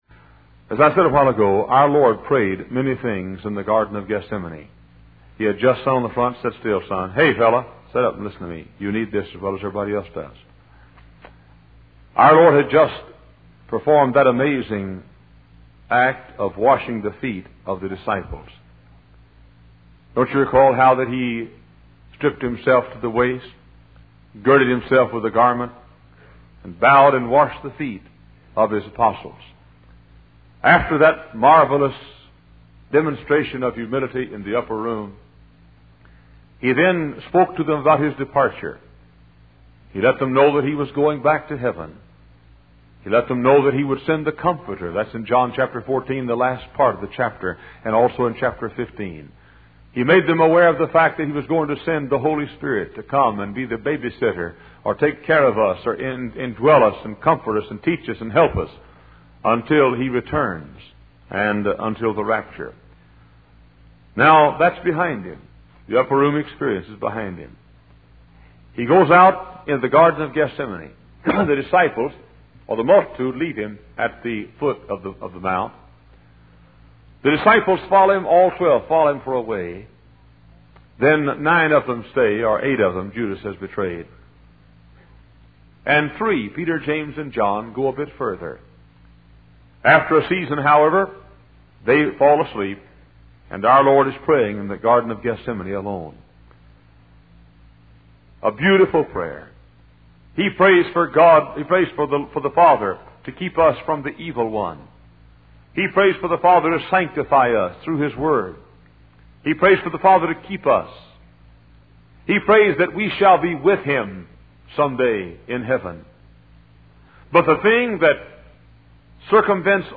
Wise Desire Ministries helps convey various Christian videos and audio sermons.